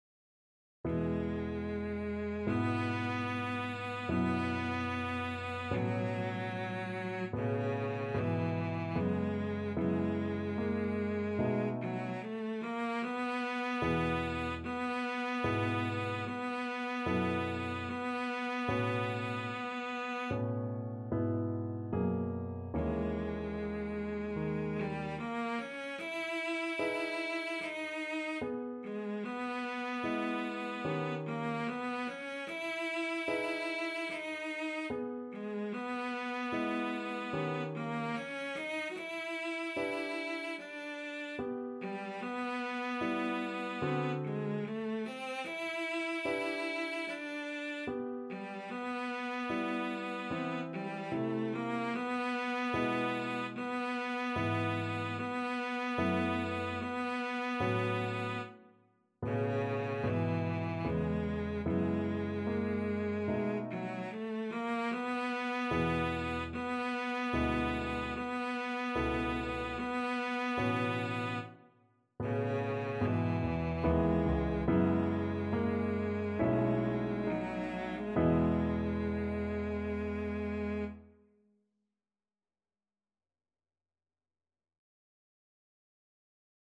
Cello
E major (Sounding Pitch) (View more E major Music for Cello )
Andante con moto =74 (View more music marked Andante con moto)
4/4 (View more 4/4 Music)
Classical (View more Classical Cello Music)